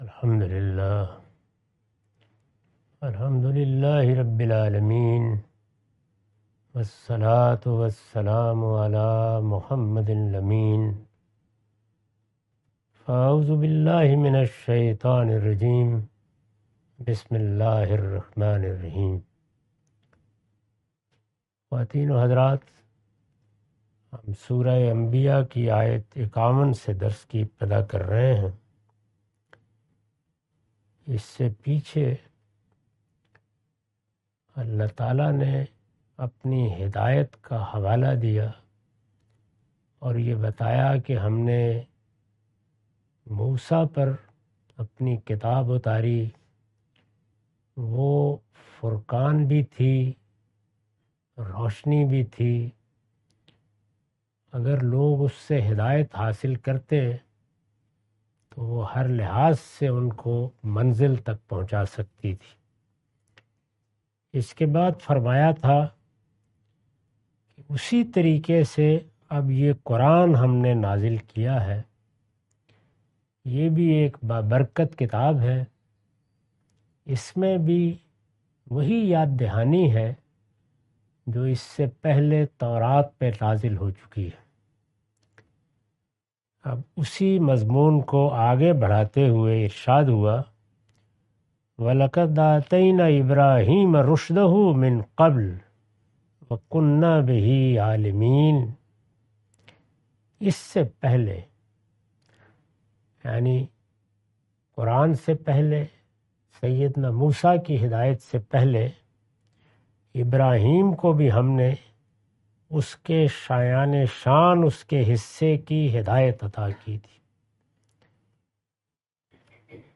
Surah Al-Anbiya A lecture of Tafseer-ul-Quran – Al-Bayan by Javed Ahmad Ghamidi. Commentary and explanation of verses 51-56.